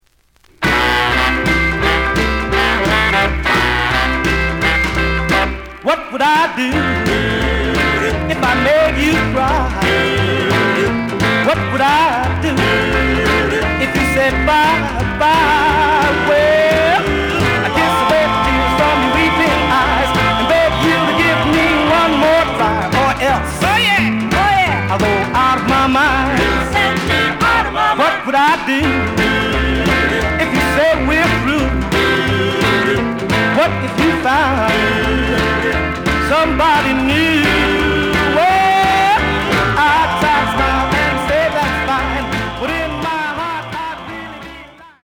The audio sample is recorded from the actual item.
●Genre: Rhythm And Blues / Rock 'n' Roll
Edge warp. But doesn't affect playing. Plays good.)